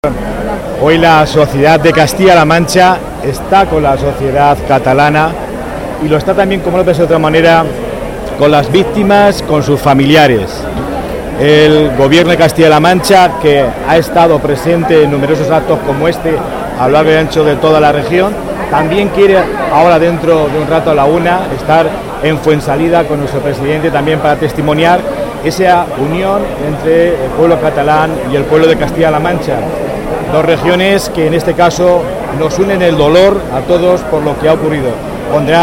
Ruiz Santos: Concentración silenciosa Albacete por atentados en Cataluña 1